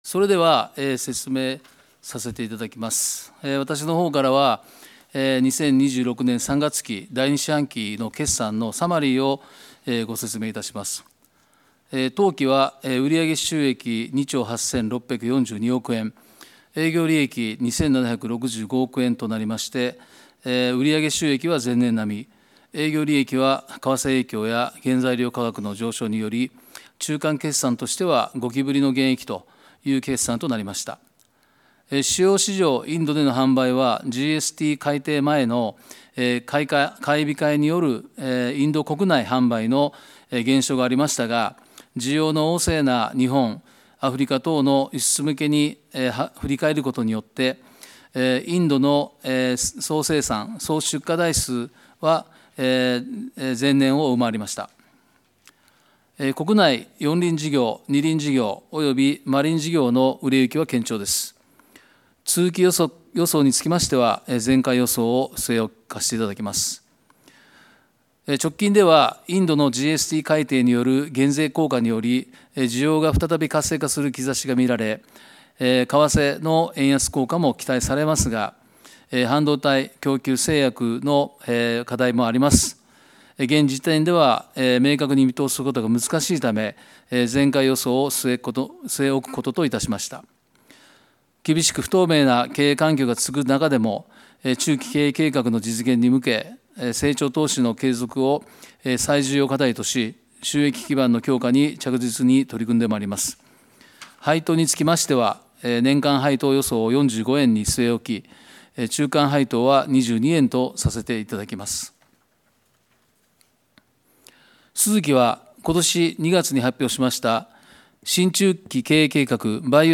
決算説明会